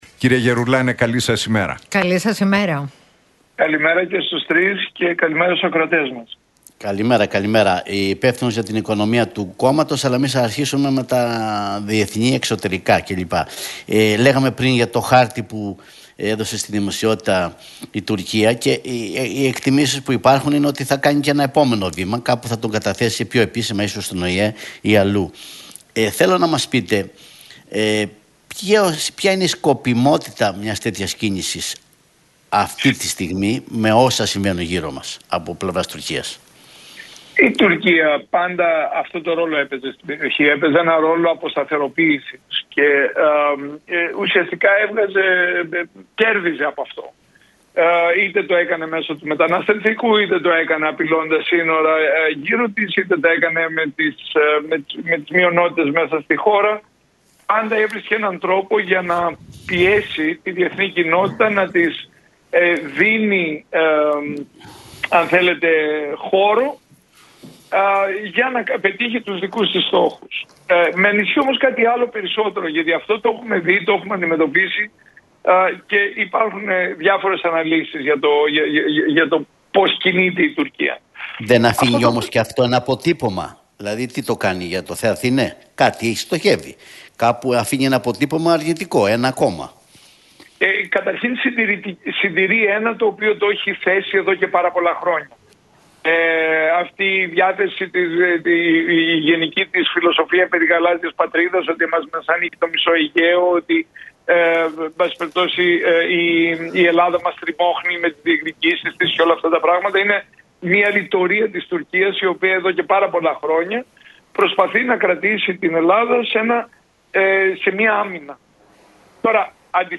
Για τις εξελίξεις στην Μέση Ανατολή, τον χάρτη με τον Θαλάσσιο Χωροταξικό Σχεδιασμό που κατέθεσε η Τουρκία στην UNESCO και την Προανακριτική Επιτροπή για τον Κώστα Καραμανλή για την υπόθεση των Τεμπών μίλησε ο κοινοβουλευτικός εκπρόσωπος του ΠΑΣΟΚ, Παύλος Γερουλάνος στους Νίκο Χατζηνικολάου